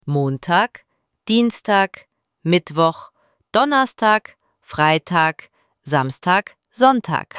IZGOVOR – DANI U SEDMICI:
ElevenLabs_Text_to_Speech_audio-49.mp3